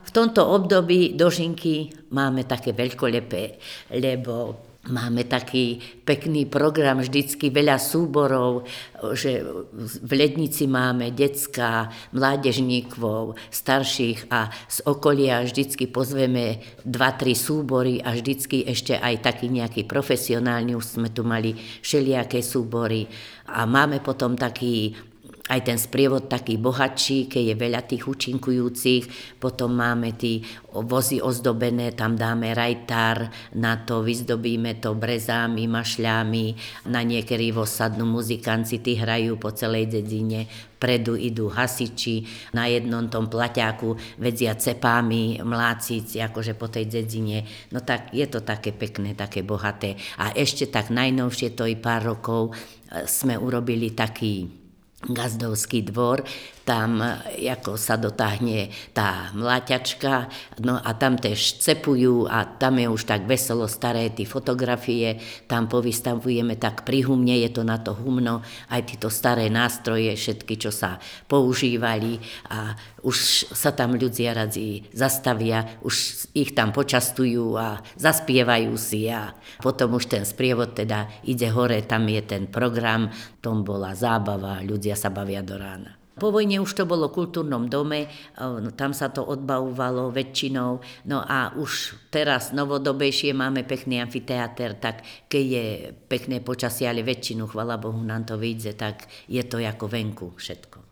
Dožinky v Lednici 001-04